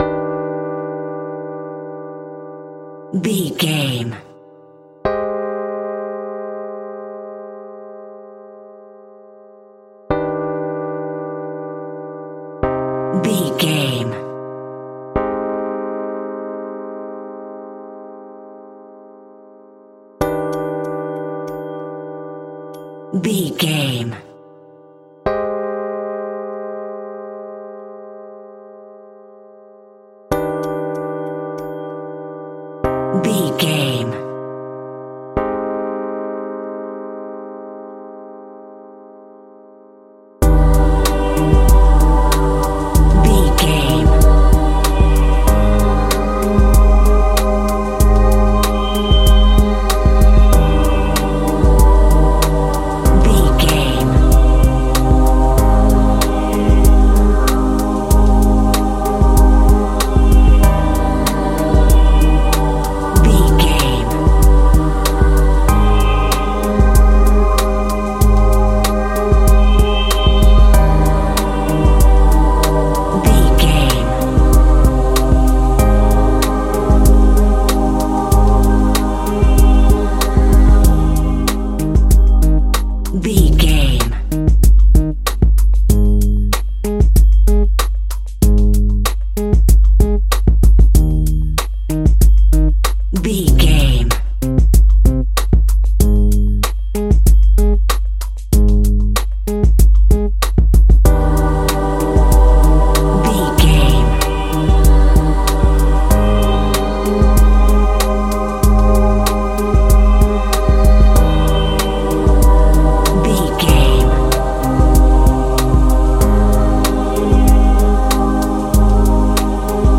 In-crescendo
Aeolian/Minor
E♭
scary
ominous
dark
eerie
groovy
piano
electric piano
drums
synthesiser
bass guitar
Horror synth